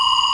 laser.mp3